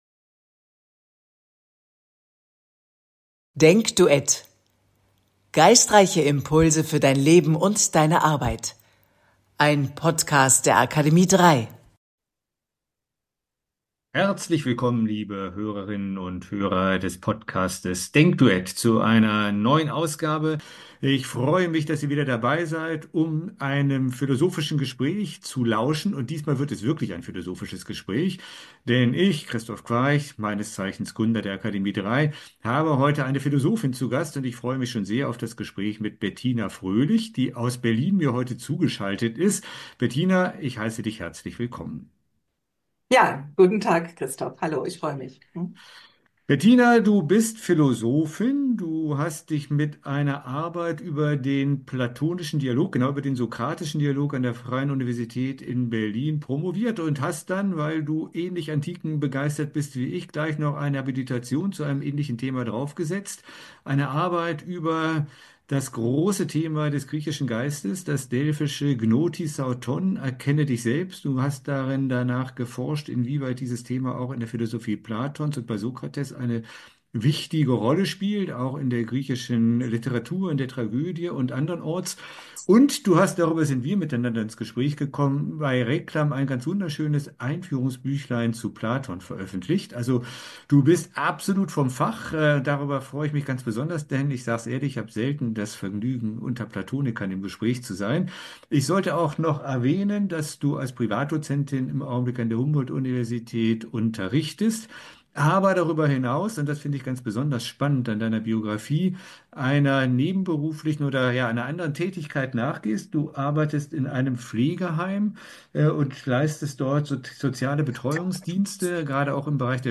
DenkDuett - ein freies Spiel der Gedanken; nicht vorgefertigt und abgerufen, sondern frisch und freihändig im Augenblick entwickelt.